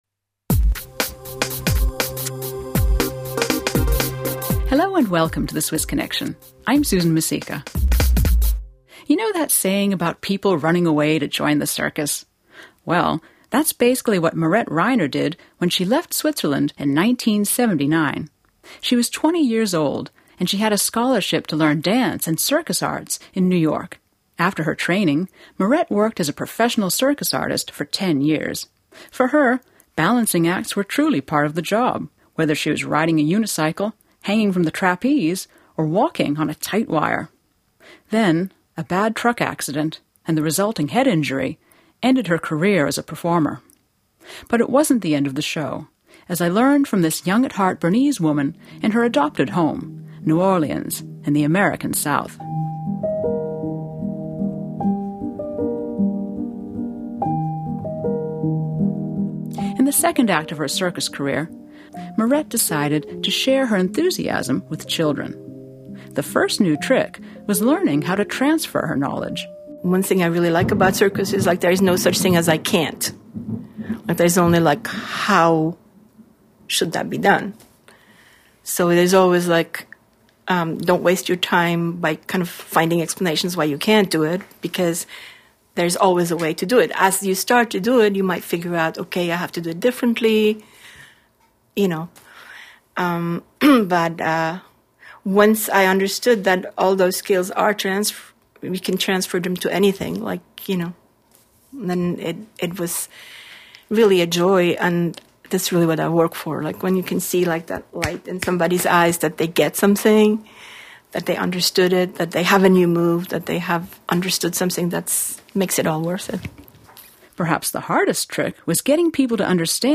people talking about circus lessons